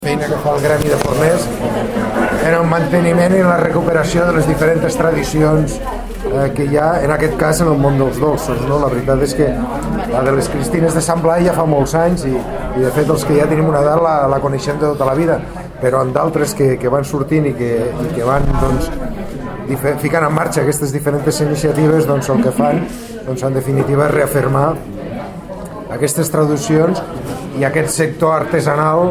Fitxers relacionats Tall de veu del tinent d'alcalde i regidor de Promoció Econòmica, Rafael Peris, sobre la Festivitat de Sant Blai i l'elaboració de Cristines de Sant Blai per part del Gremi de Forners (455.1 KB)